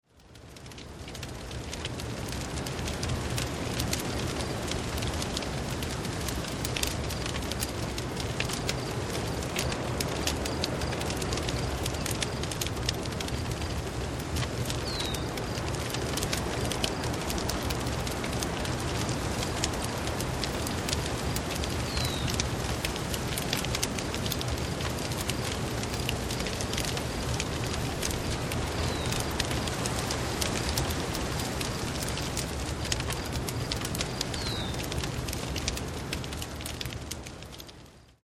6 Stunden Lagerfeuer mit Grillenzirpen
Bei Geräuschaufnahmen sind diese ebenfalls in 44.1 kHz Stereo aufgenommen, allerdings etwas leister auf -23 LUFS gemastert.
44.1 kHz / Stereo Sound
Lautstärke: -23 LUFS
Hoerprobe-Grillenzirpen.mp3